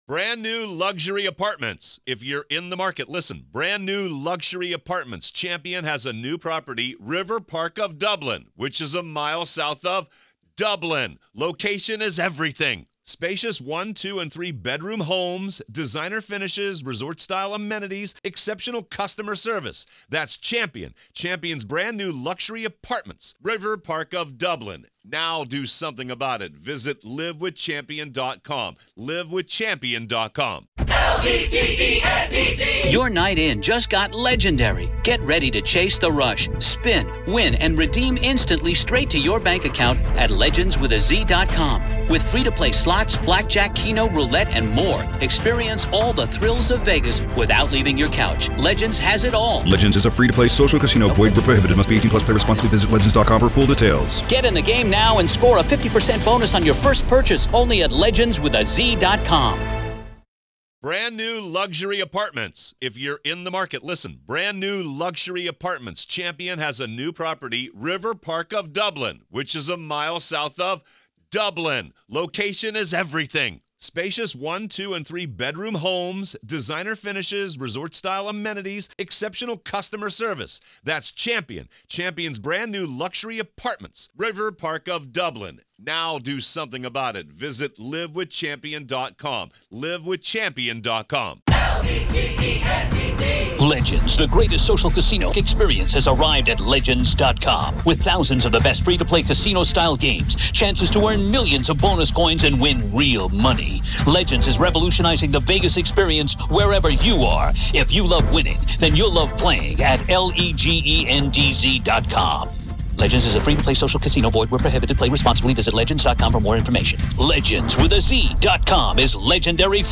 This is our continuing coverage of the Alex Murdaugh murder trial.